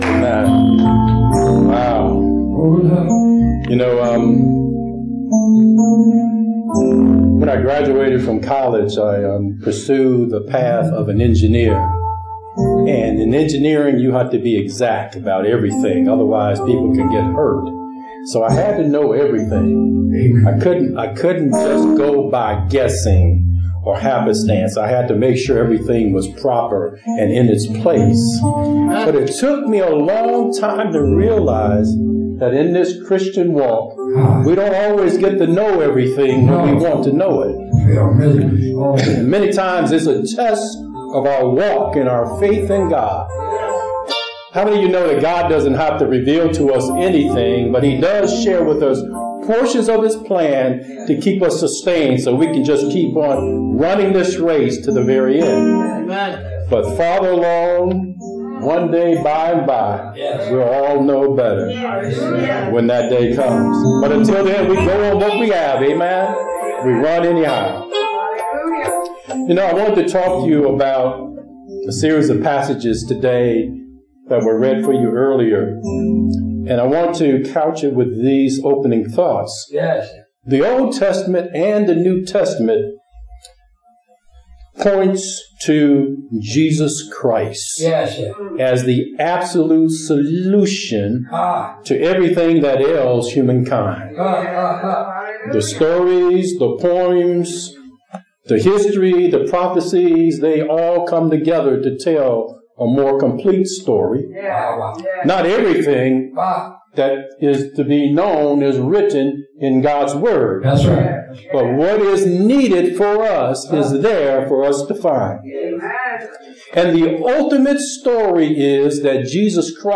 Sermons | Truth Teaching Ministries